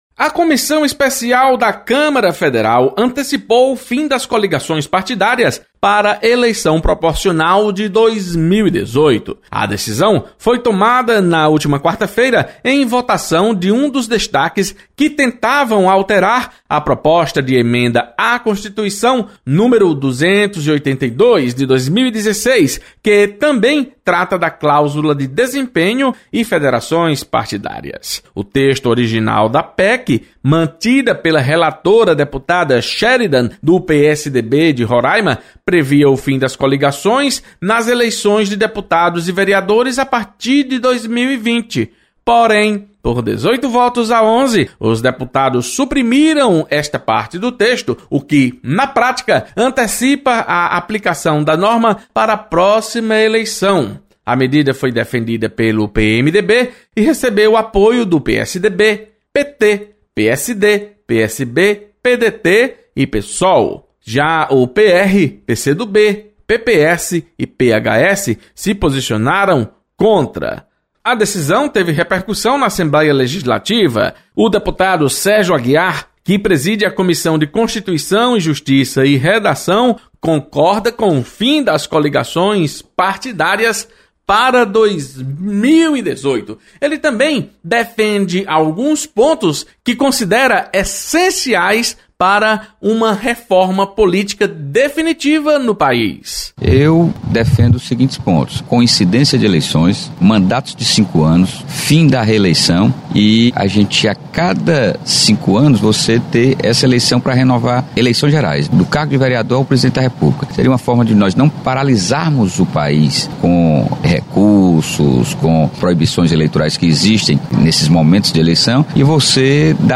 Deputado Sérgio Aguiar comenta sobre fim das coligações para eleições proporcionais de 2018.